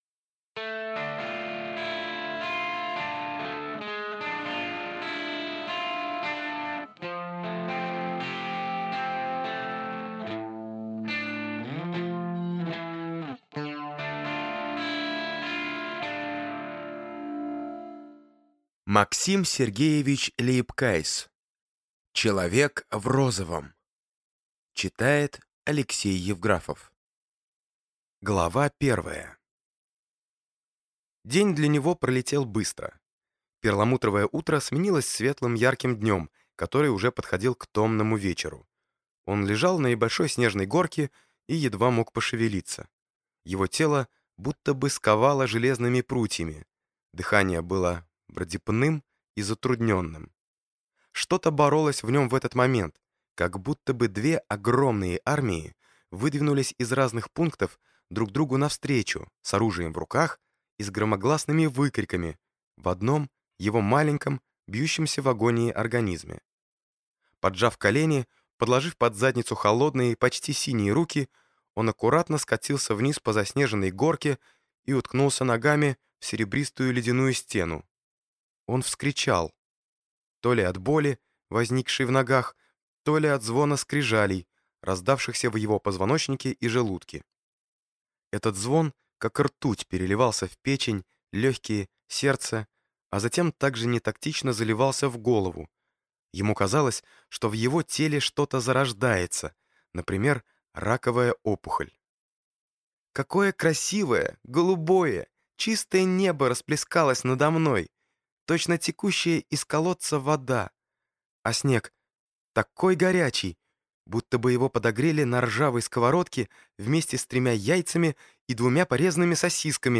Аудиокнига Человек в розовом | Библиотека аудиокниг